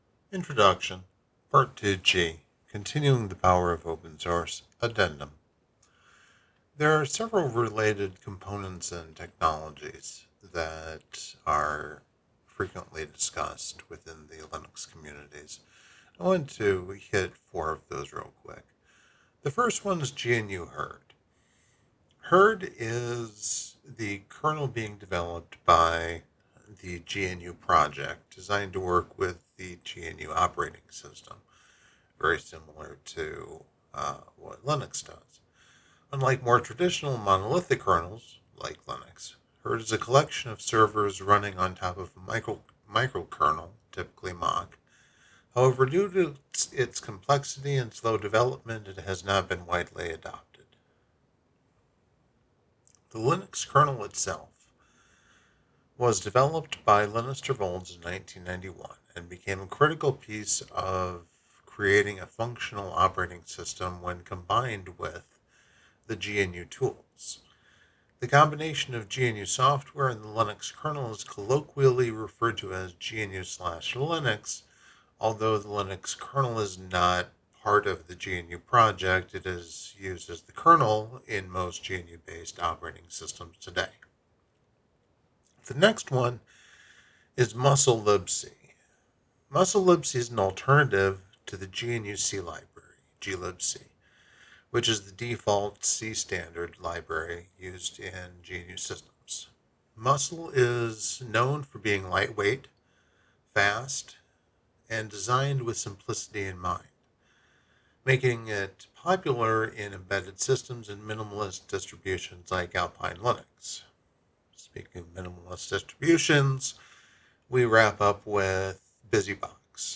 'Audiobook